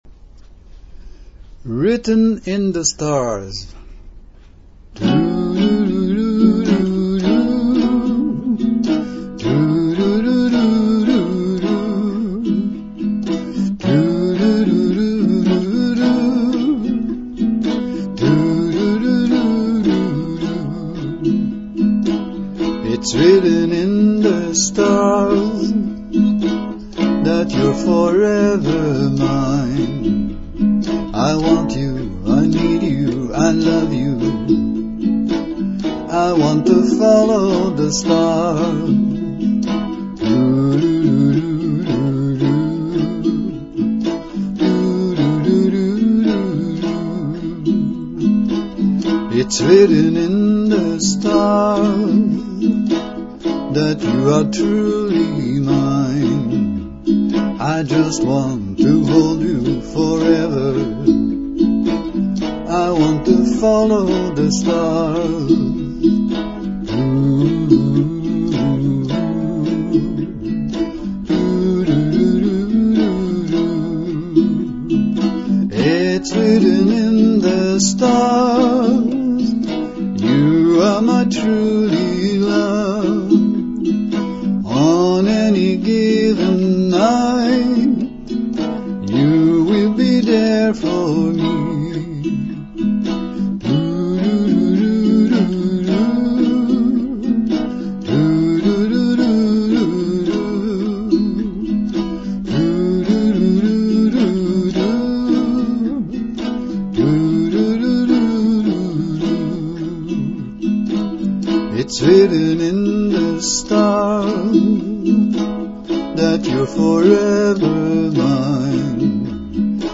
writteninthestarsuke.mp3
Intro: 8 bars (key of E)